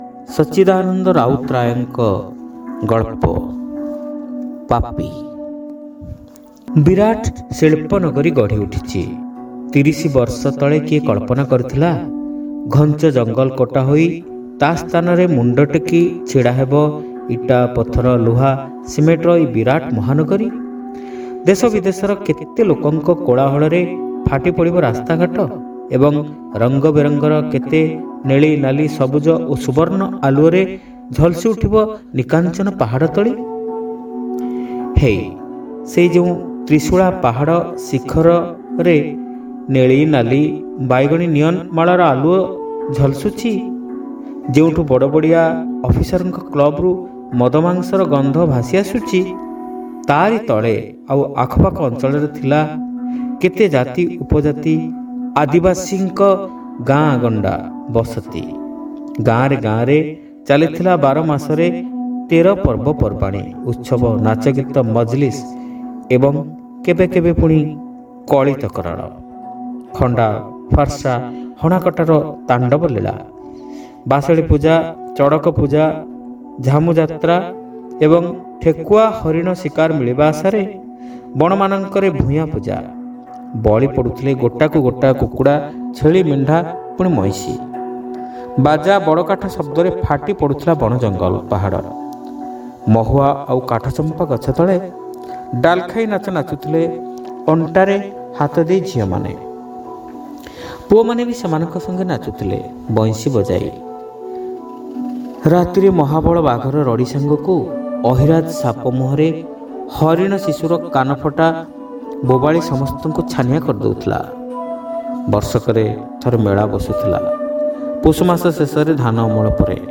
ଶ୍ରାବ୍ୟ ଗଳ୍ପ : ପାପୀ (ପ୍ରଥମ ଭାଗ)